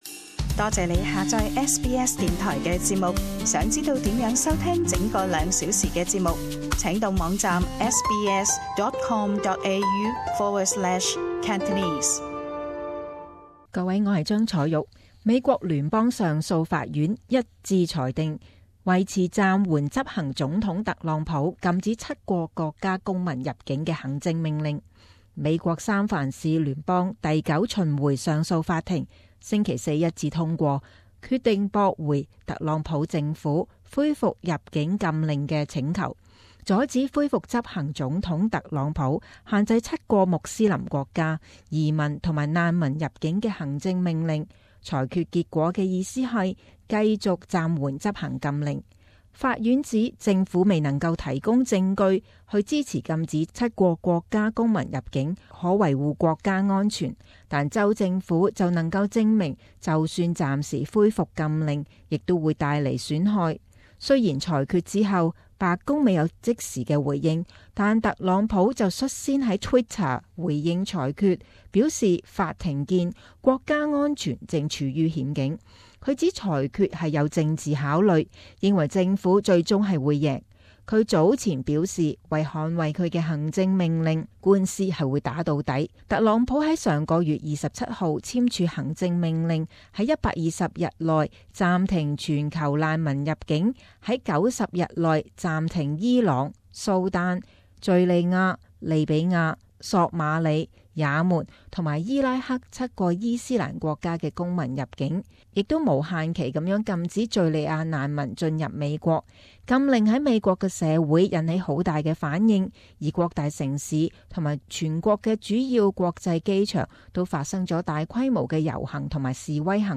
【時事報導】法庭裁定維持暫緩入境禁令